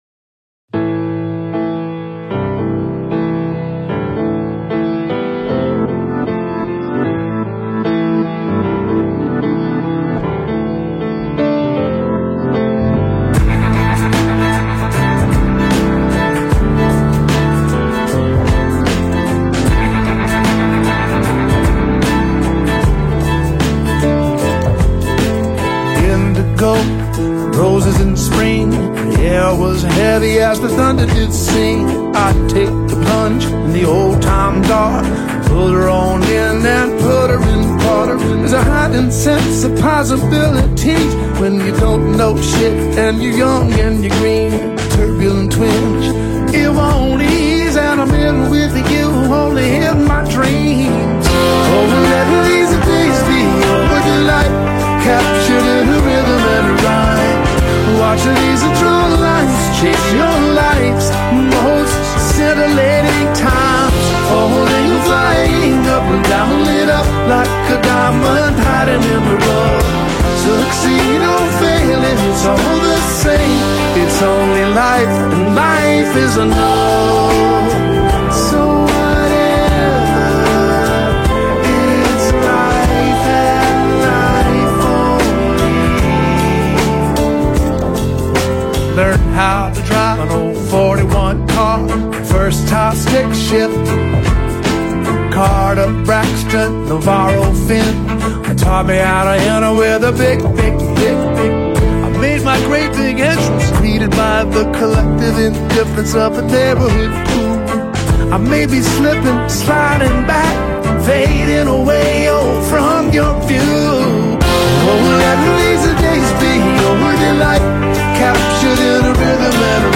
There is space in this track.